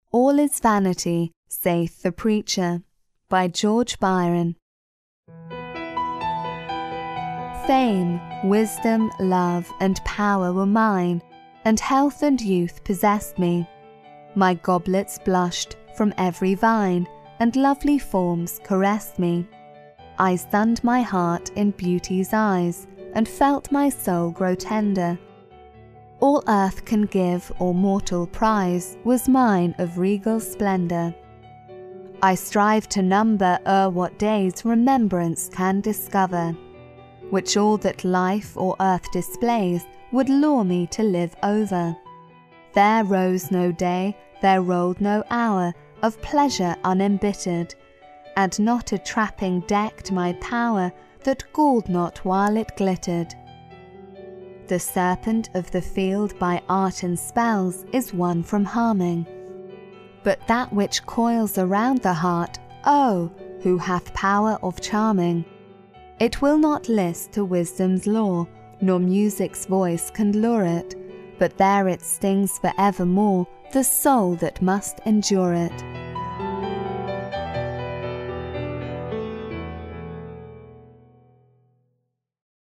双语有声阅读：传道者说：凡事都是虚空 听力文件下载—在线英语听力室